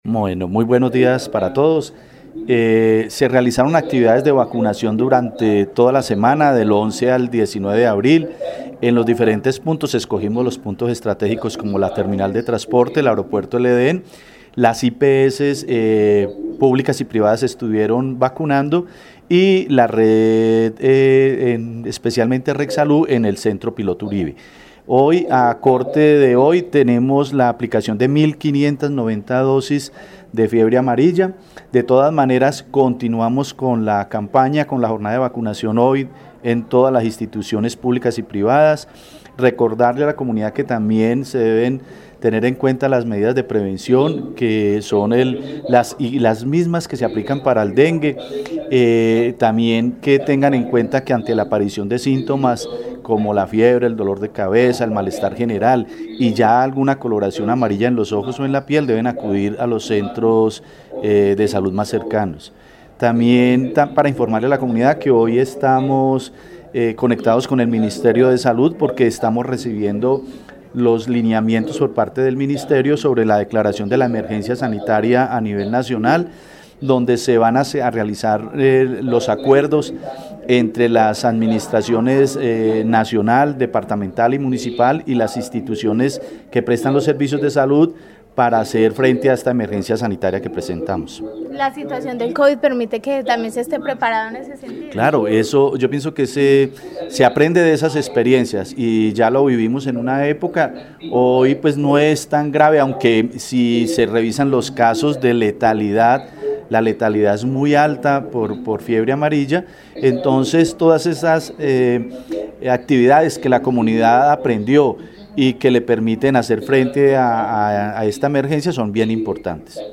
Secretario de Salud de Armenia